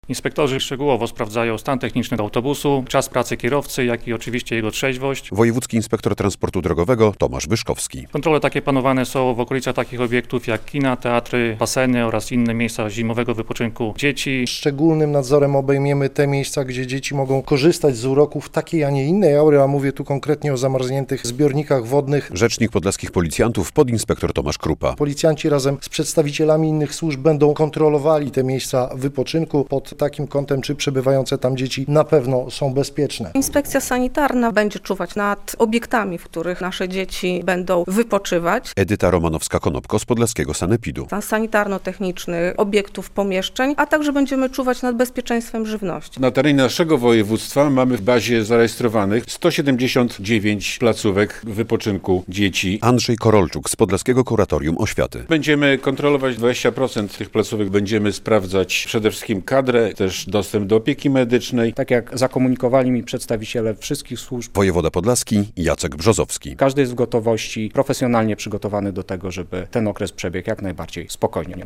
Bezpieczne ferie - relacja